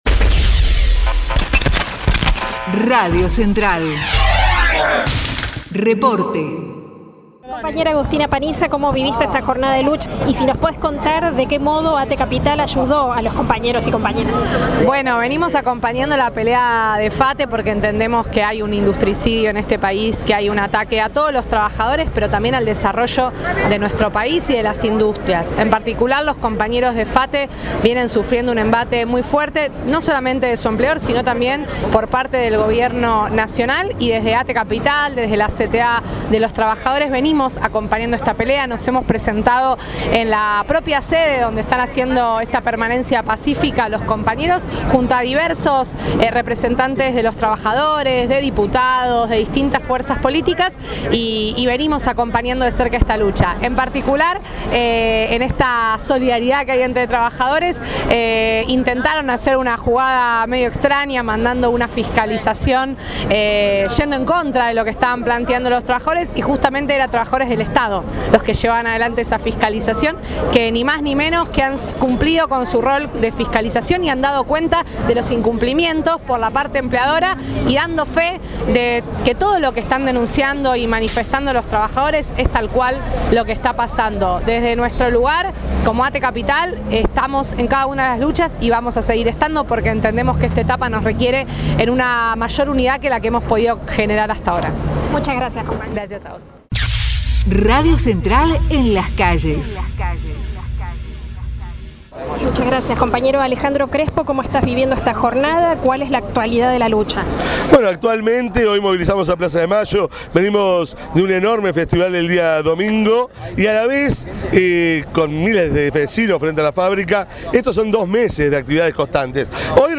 MARCHA EN APOYO A FATE - PLAZA DE MAYO
2026_marcha_en_defensa_de_fate.mp3